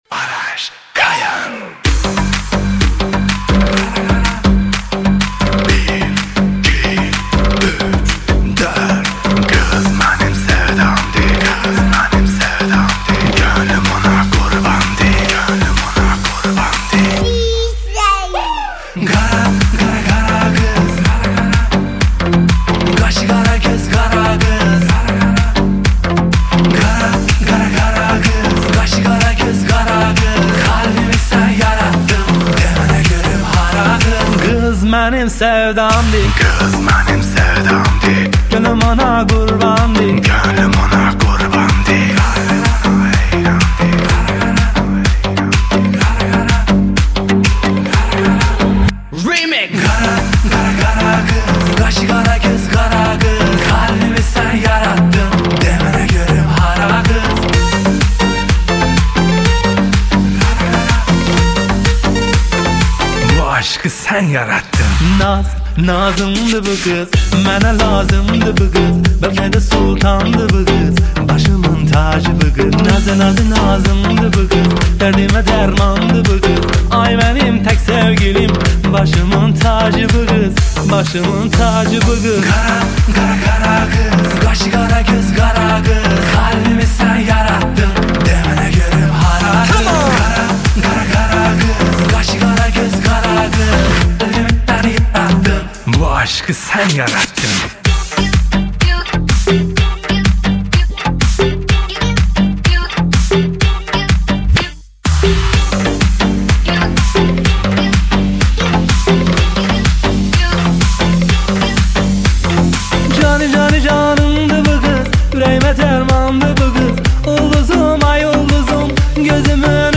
آهنگ ترکی
آهنگ آذری
شاد